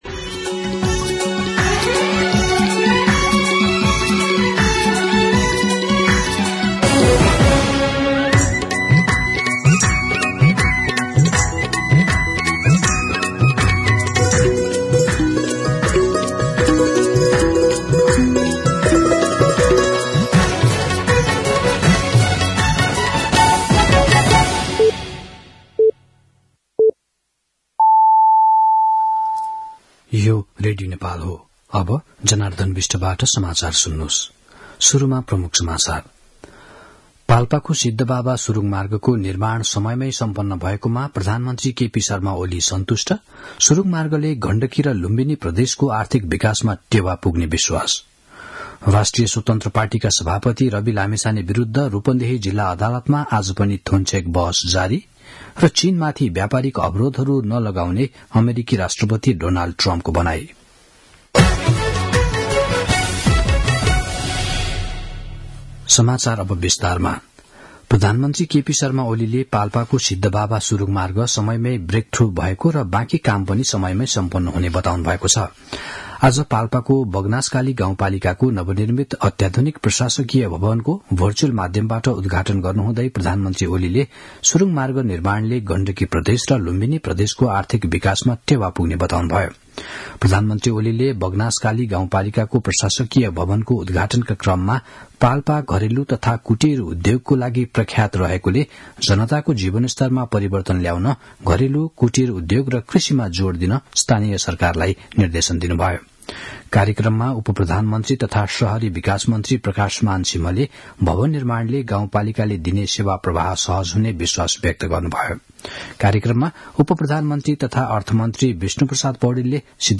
दिउँसो ३ बजेको नेपाली समाचार : १२ माघ , २०८१
3-pm-news-3.mp3